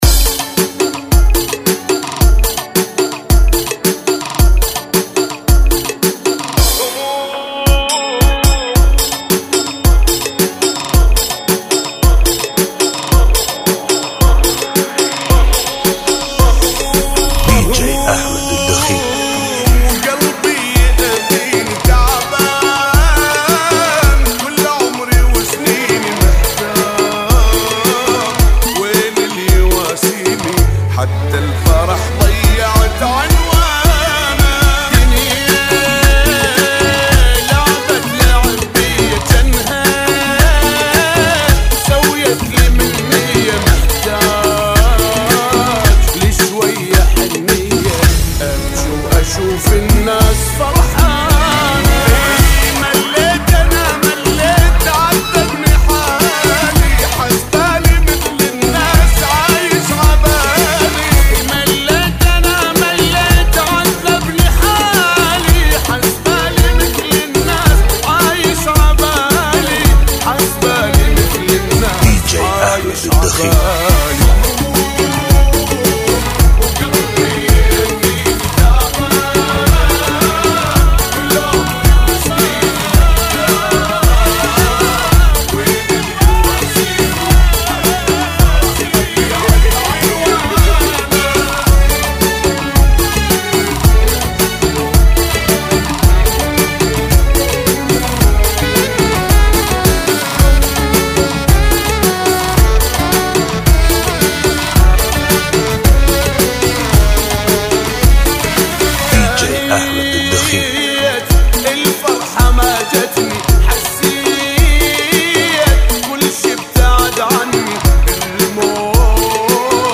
ريمكس
Funky Remix